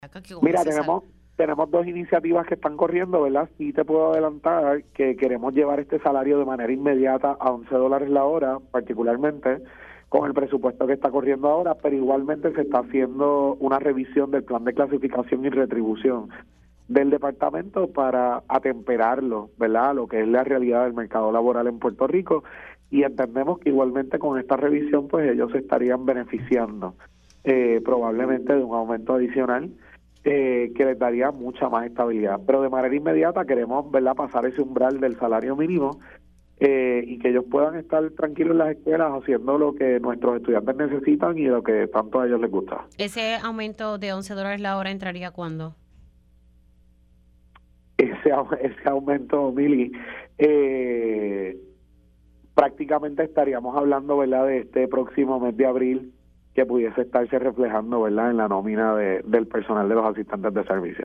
Ramos Parés especificó en entrevista para Pega’os en la Mañana que el salario sería de $11 por hora y reconoció que el reclamo por parte de este grupo de profesionales ha sido consistente, por lo que en los próximos días pudiera informar detalles adicionales al respecto.